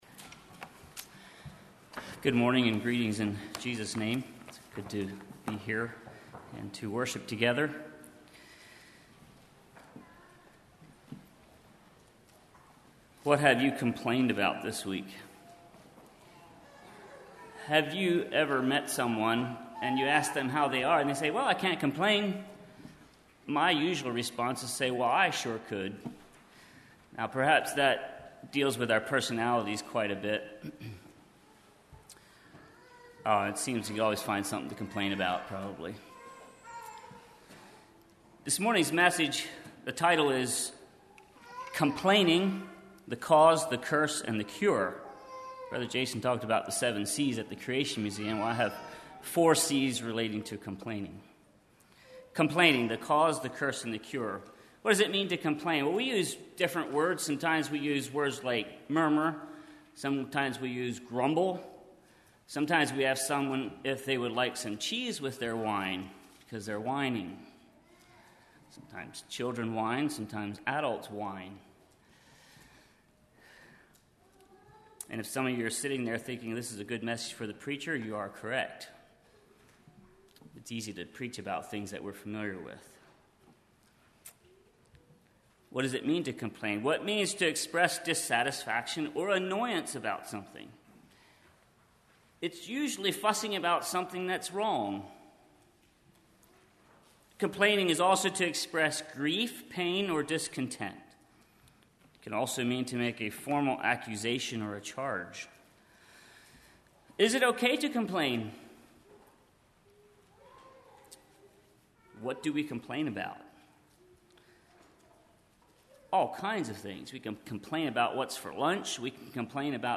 Congregation: Swatara